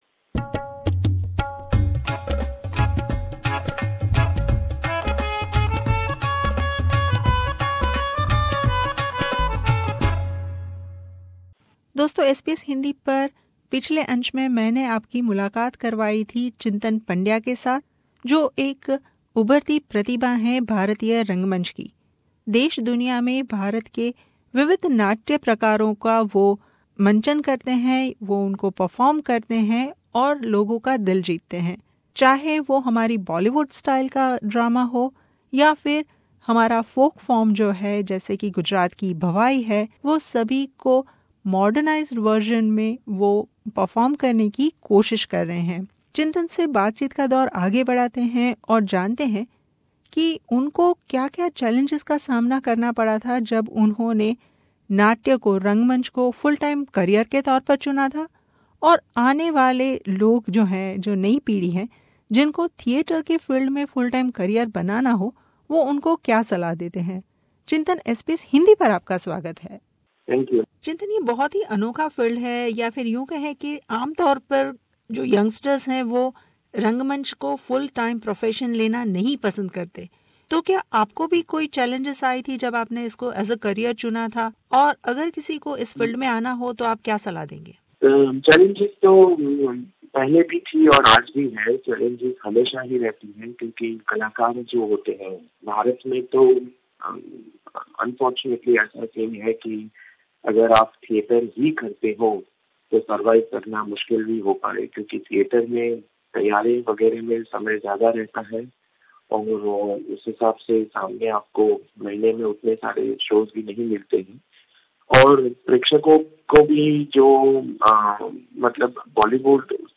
मुलाकात